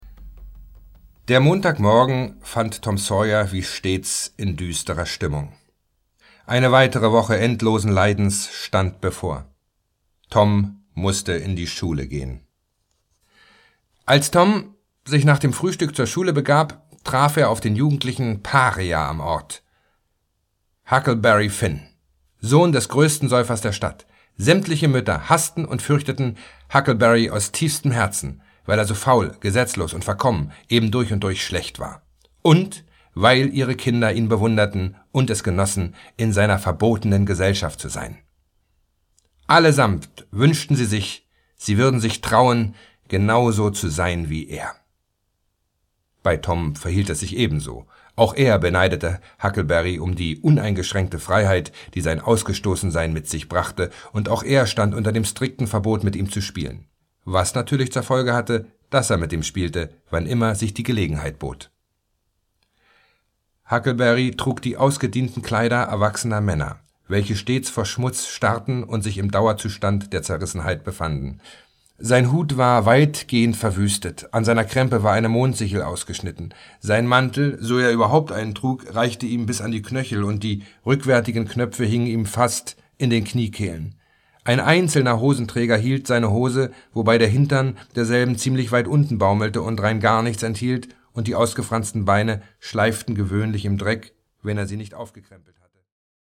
Leander Haußmann (Sprecher)
»Mit jungenhafter Fabulierlust, viel Verve, berlinerndem Tonfall, wo er in den Dialogen passt, und einer Inszenierungslaune wie in seinen besten Tagen am Bochumer Schauspielhaus legt Leander Haußmann mehr als bloß eine weitere Akustikfassung des Weltliteraturstoffes vor.« Hessische-Niedersächsische Allgemeine